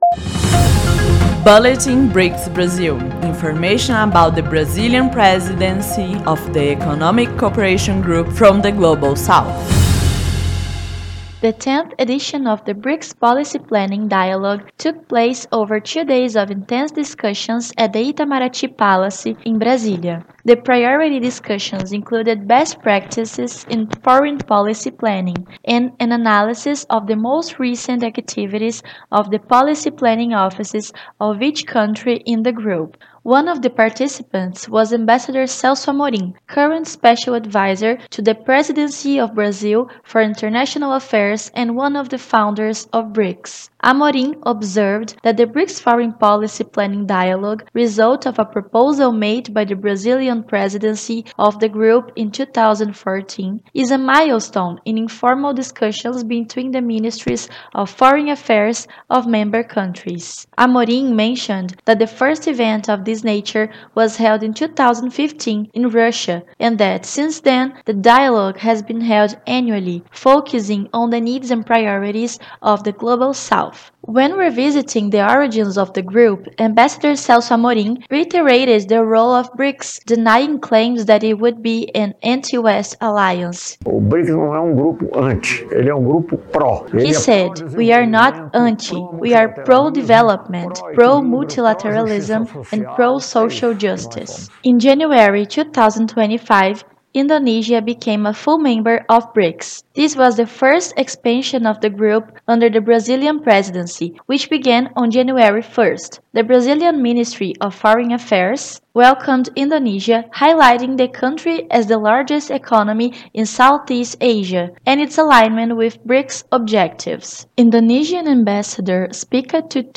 One of the founders of BRICS and current special advisor to the Presidency of Brasil for International Affairs, Celso Amorim reiterates the role of the group as a multilateral alternative. Amorim took part in the 10th BRICS Policy Planning Dialogue held in Brasília this week. Listen to the report and learn more.